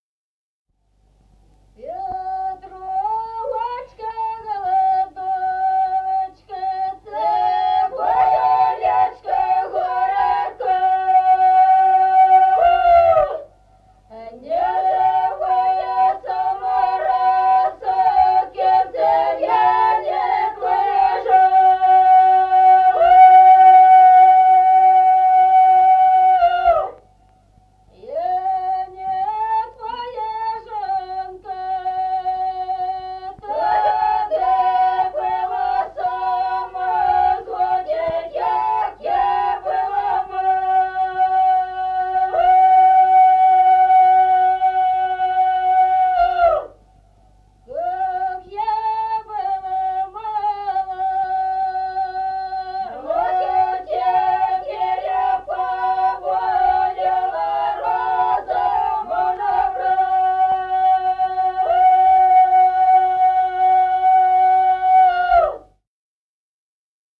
Песни села Остроглядово. Петровочка-голодовочка (петровочная).